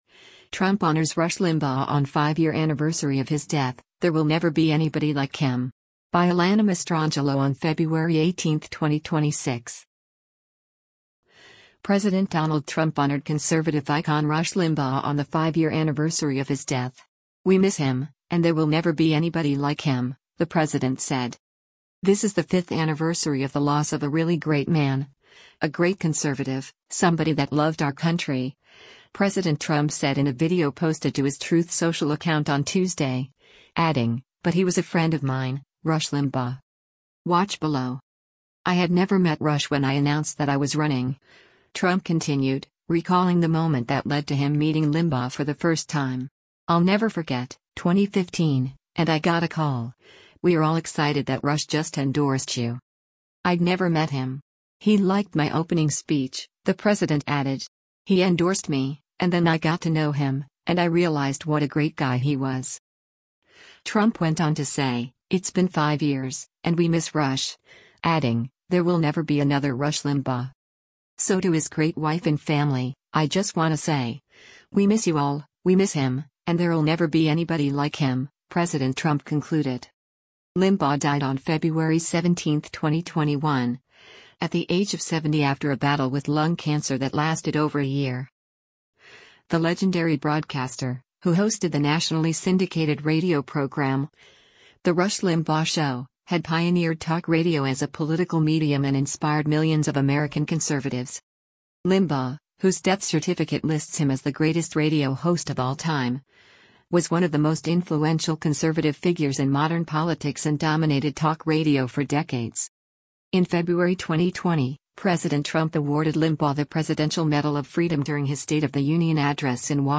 “This is the fifth anniversary of the loss of a really great man, a great conservative, somebody that loved our country,” President Trump said in a video posted to his Truth Social account on Tuesday, adding, “But he was a friend of mine — Rush Limbaugh.”